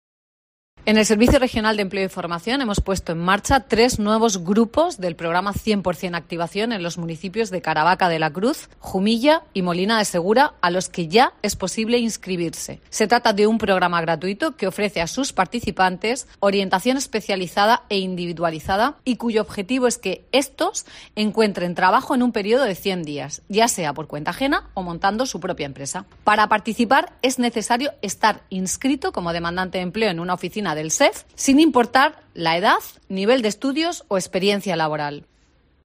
Marisa López Aragón, directora general del SEF